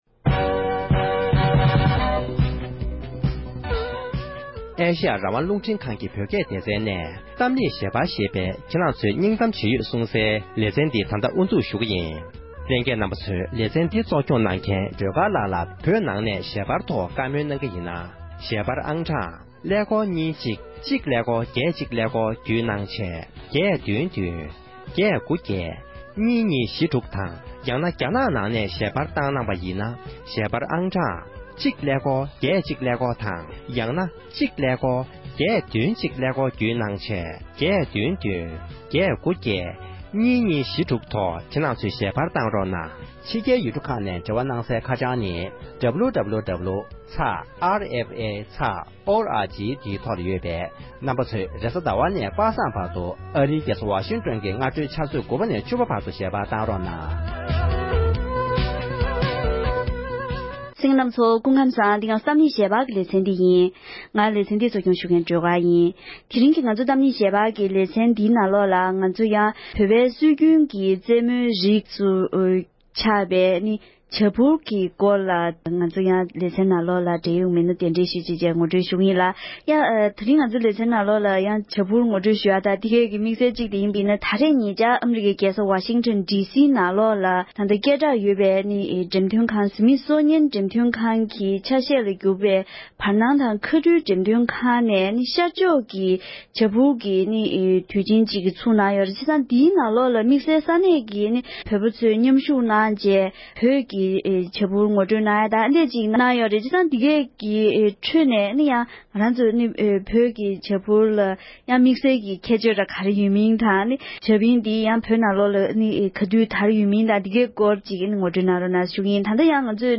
འབྲེལ་ཡོད་མི་སྣའི་ལྷན་གླེང་མོལ་གནང་བར་གསན་རོགས༎